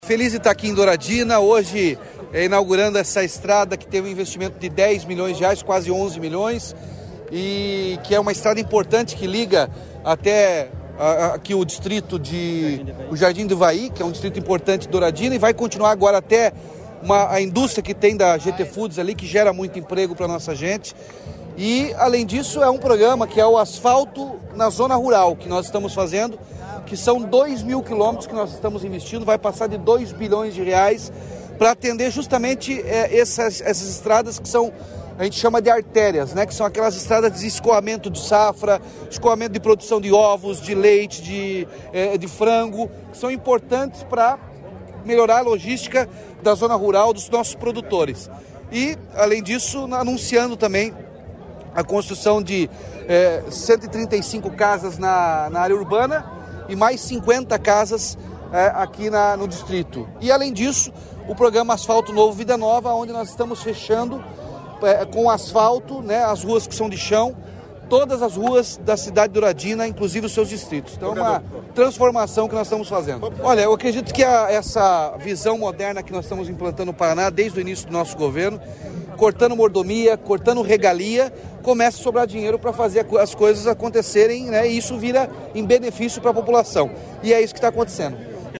Sonora do governador Ratinho Junior sobre a pavimentação de estrada que liga sede a distrito de Douradina